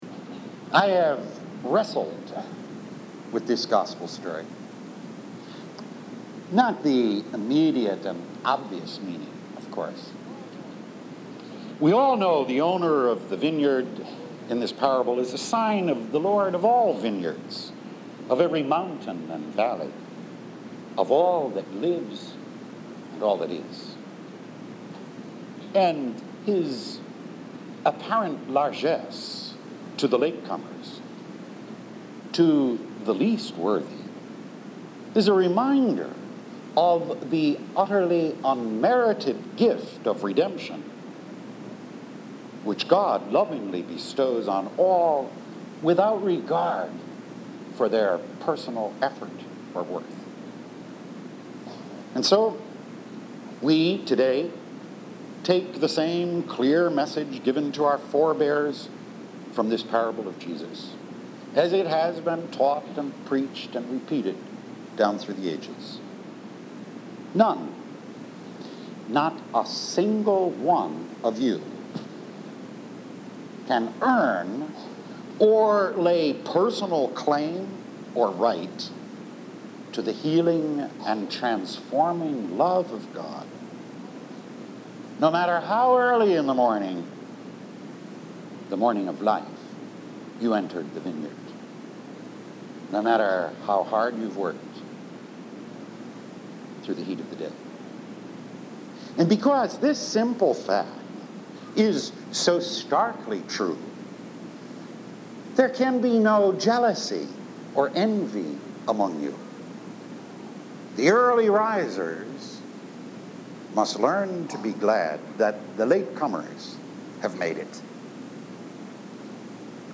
25th Sunday in Ordinary Time « Weekly Homilies